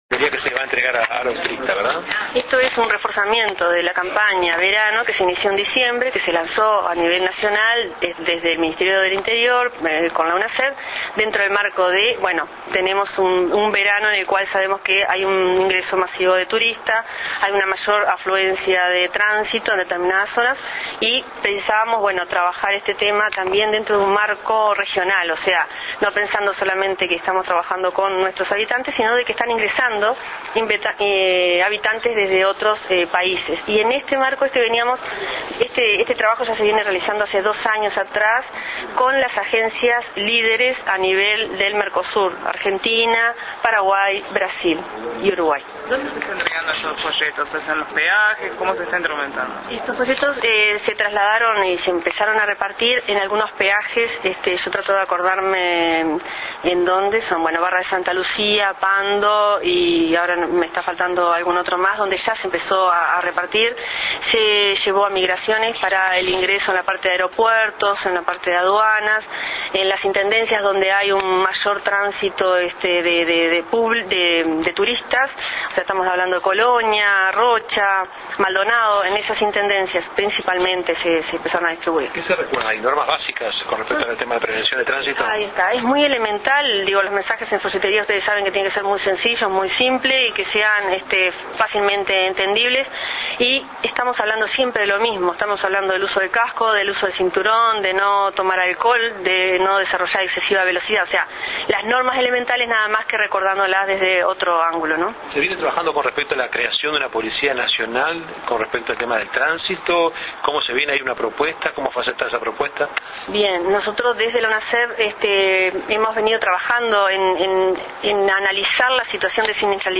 Declaraciones a la prensa de la Secretaria Ejecutiva de UNASEV, Gaby Lencina.